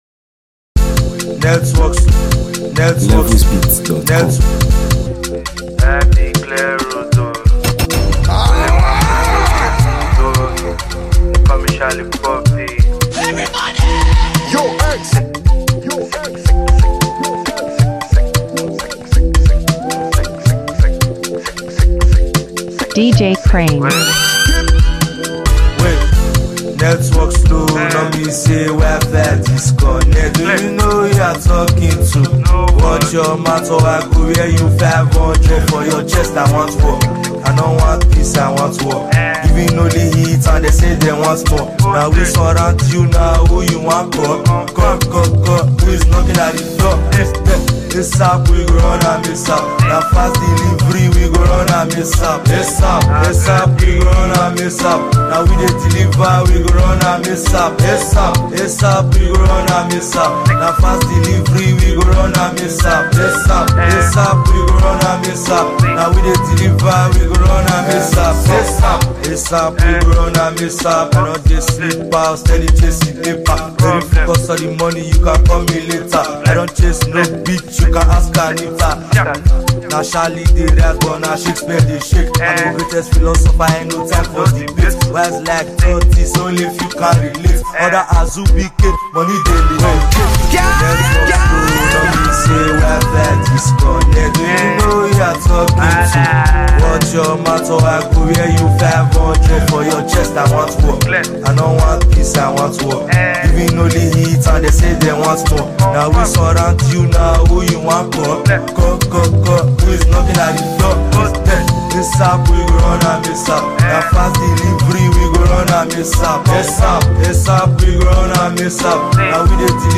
a skilled and energetic disc jockey
mixtape
DJ mixes